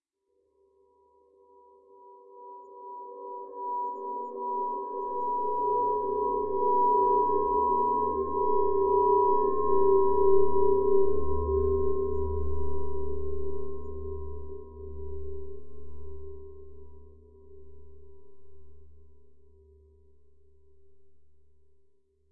描述：由大量处理过的人声录音产生的无人机
Tag: 雄蜂 处理 语音